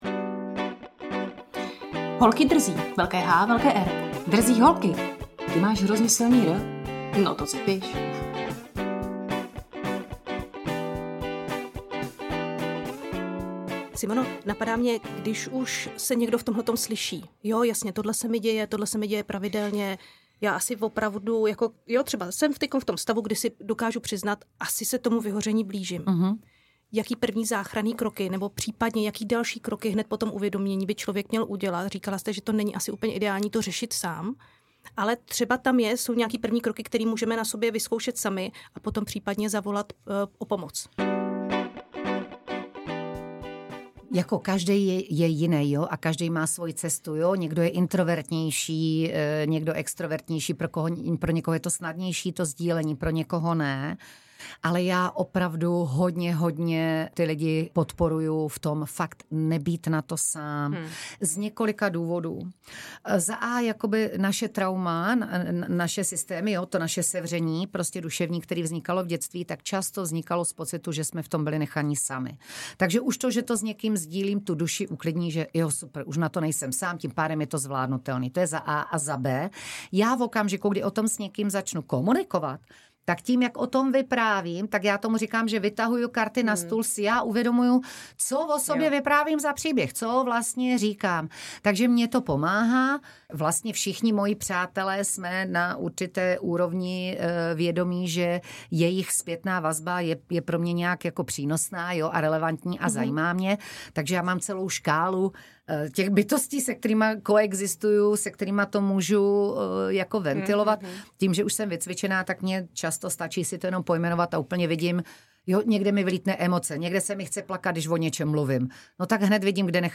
Tento díl navazuje na předchozí část rozhovoru se Simonou Babčákovou.